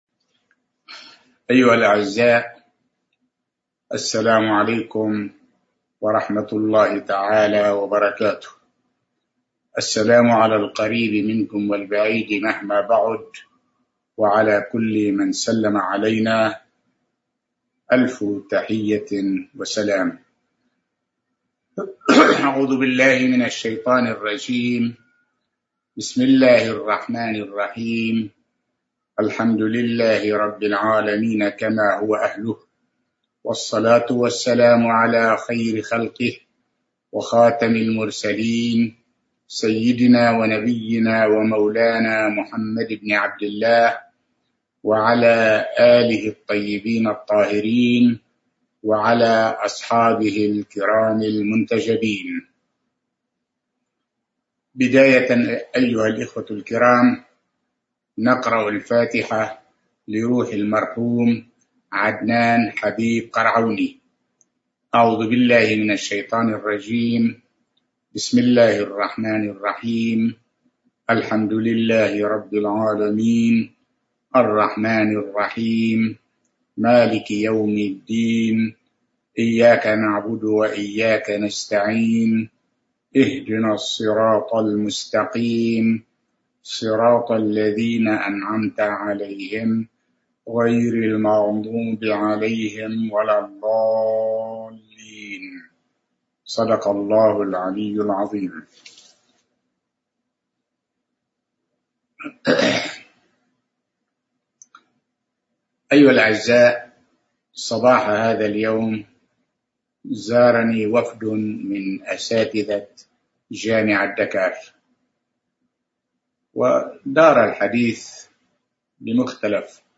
سلسلة محاضرات
ألقاها في منزله في السنغال